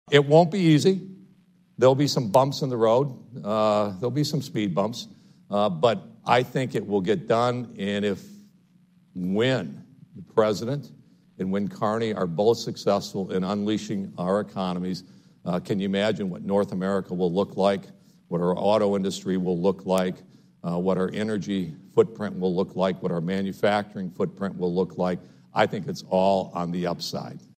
The one-time Congressman from Holland, who was the US Ambassador to the Netherlands during the first Trump Administration, spoke at the Detroit Regional Chamber’s Mackinac Policy Conference on Wednesday. While admitting some issues in relations between Washington and Ottawa, calling them “pebbles in the shoe” obstacles, Hoekstra is confident that a trade deal, among other things, will be worked out.